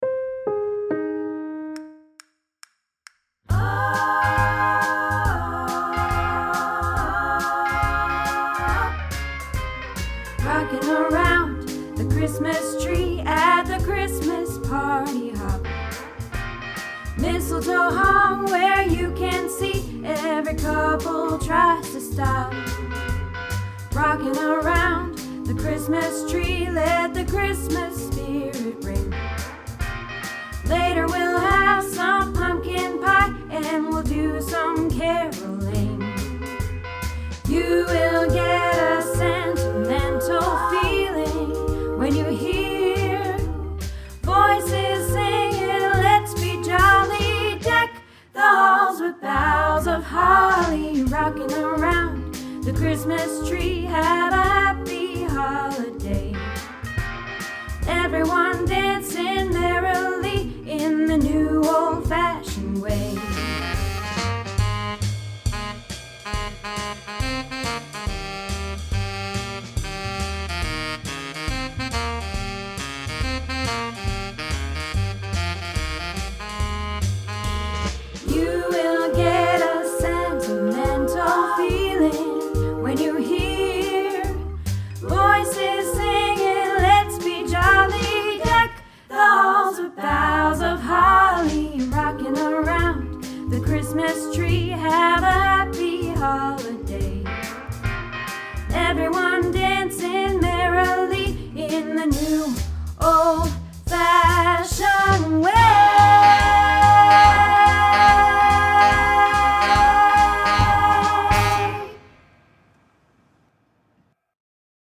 Rockin Around the Christmas Tree - Practice